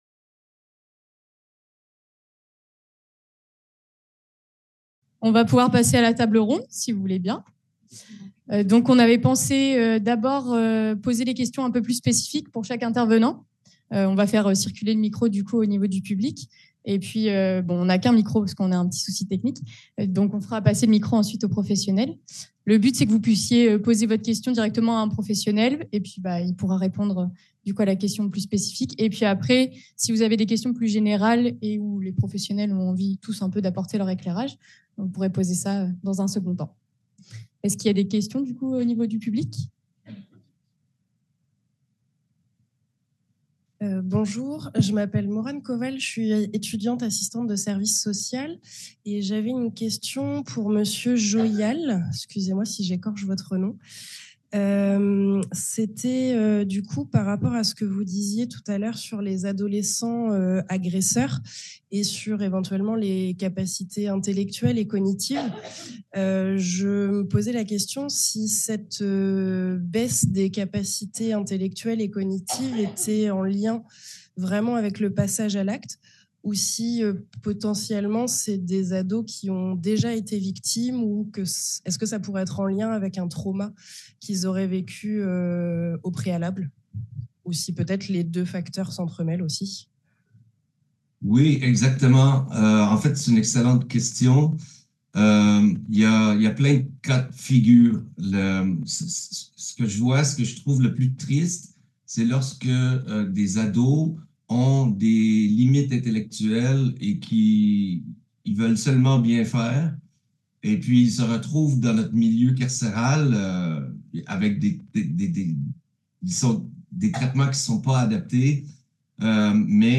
Table ronde séminaire les violences conjugales